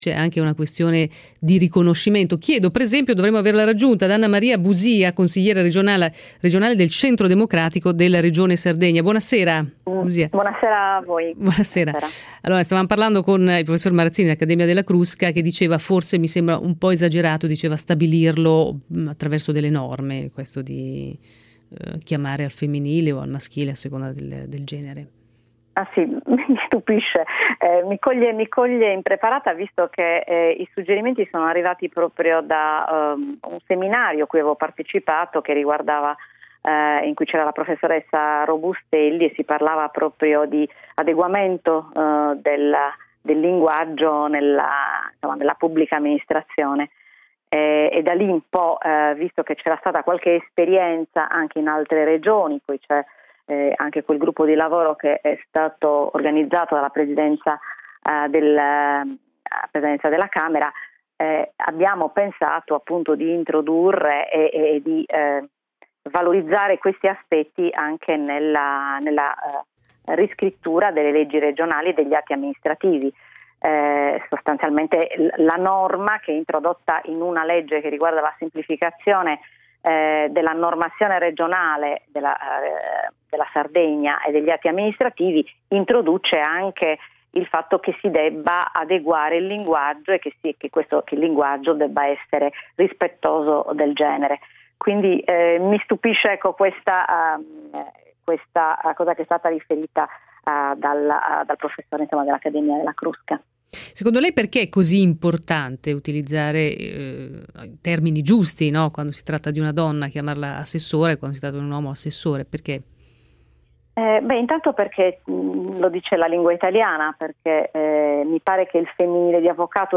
Intervista radiofonica della on. Anna Maria Busia a Effetto Notte del 12/10/2016